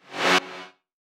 VEC3 Reverse FX
VEC3 FX Reverse 23.wav